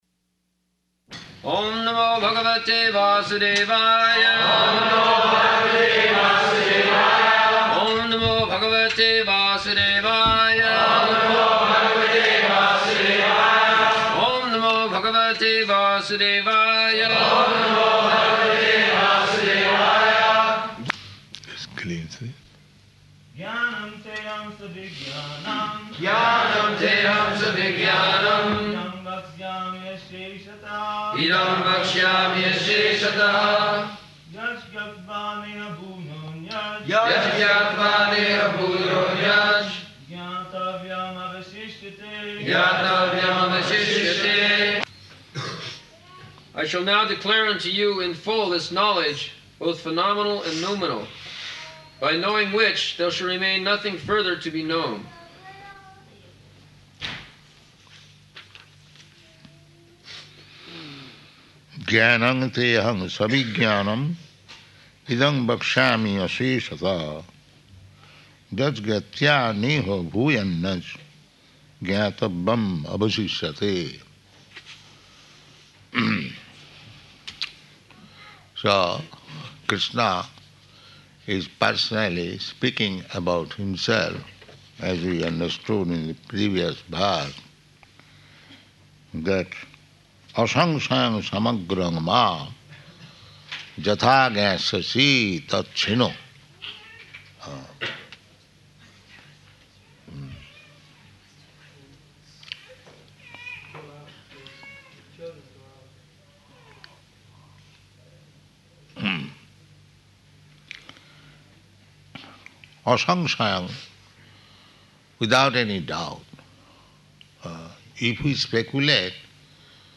March 10th 1975 Location: London Audio file
[devotees repeat] [leads chanting of verse, etc.]